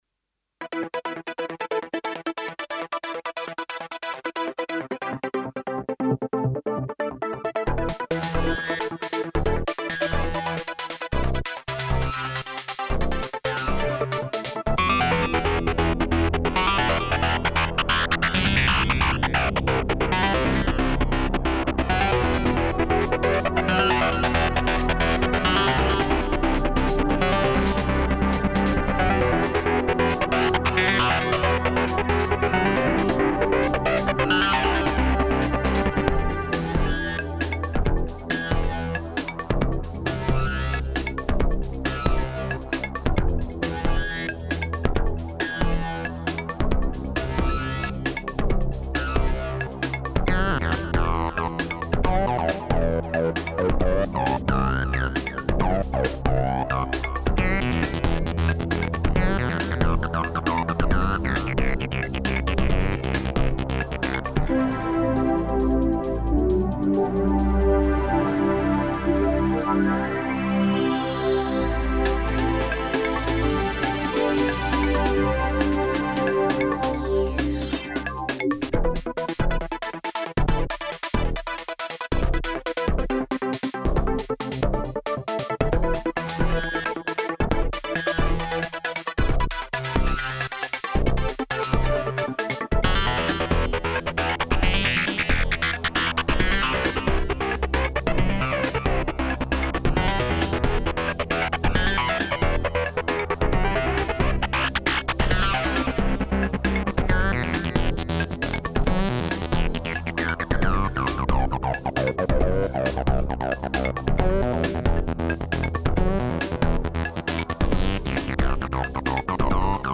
demoAUDIO DEMO
Factory demo
Class: Synth Module
Synthesis: Virtual Analog